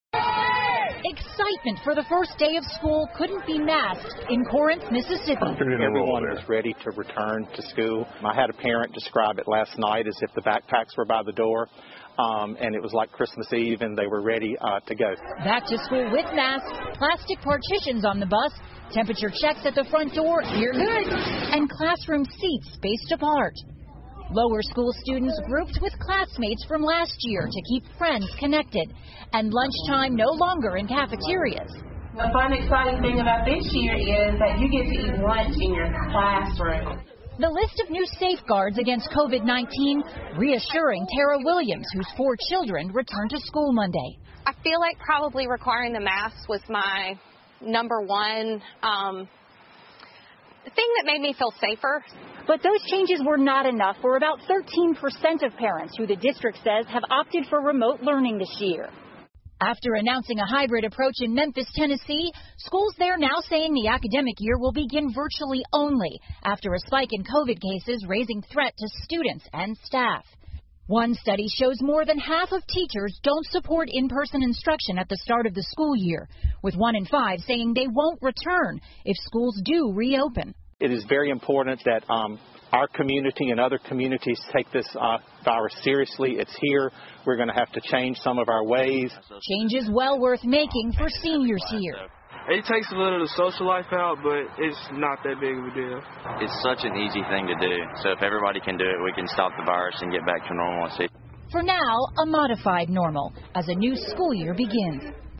NBC晚间新闻 疫情下美国学校开始重启 听力文件下载—在线英语听力室